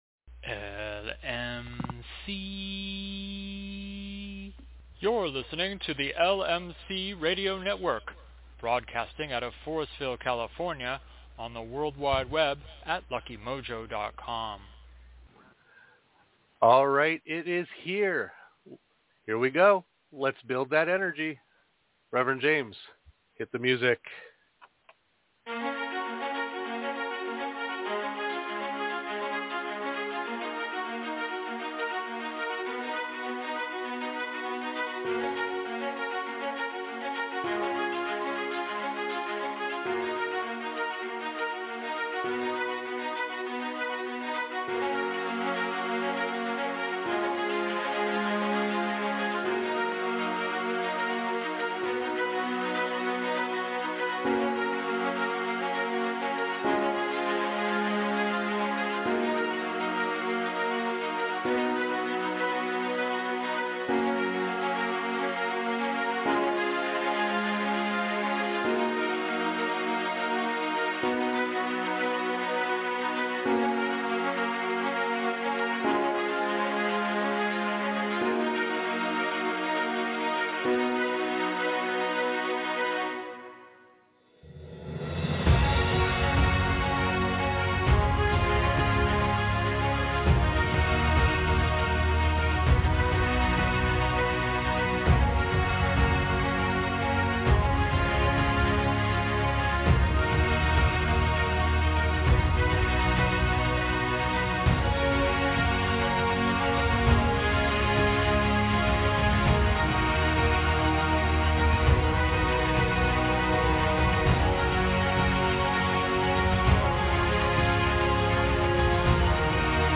Call in to the show LIVE, 06/24/2024, between 7-8:30PM EST (4-5:30PST), press 1 to raise your hand and ask us on air!